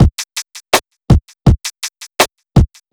HP082BEAT3-L.wav